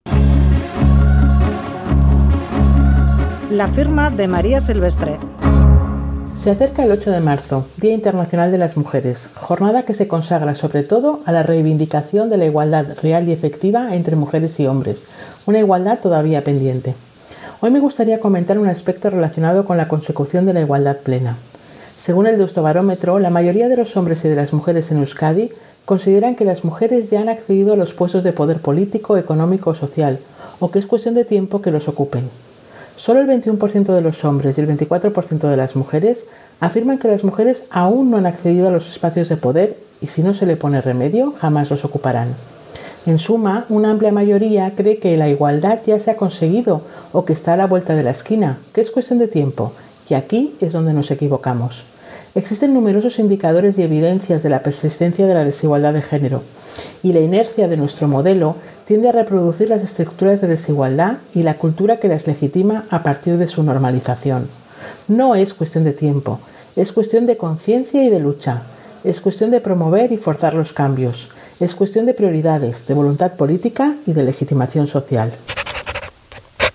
Radio Euskadi LA FIRMA 8M y la igualdad pendiente Publicado: 04/03/2021 08:15 (UTC+1) Última actualización: 04/03/2021 08:15 (UTC+1) Columna radiofónica de opinión en Boulevard de Radio Euskadi Whatsapp Whatsapp twitt telegram Enviar Copiar enlace nahieran